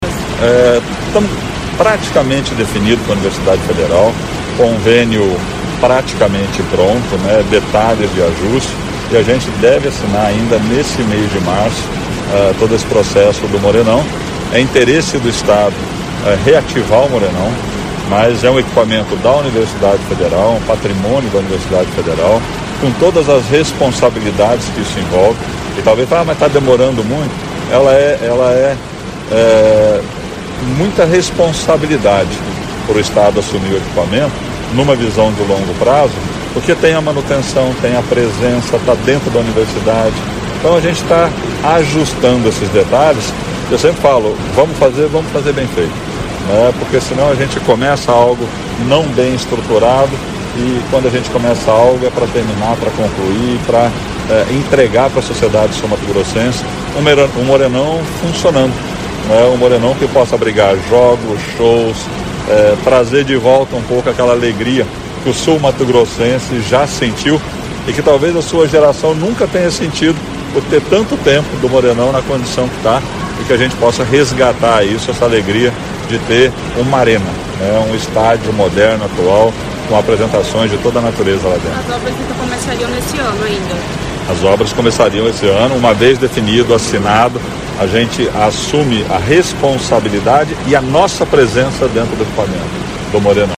Entrevista do governador Eduardo Riedel